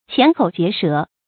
鉗口結舌 注音： ㄑㄧㄢˊ ㄎㄡˇ ㄐㄧㄝ ˊ ㄕㄜˊ 讀音讀法： 意思解釋： 鉗口：嘴巴張不開；結舌：舌頭轉不動。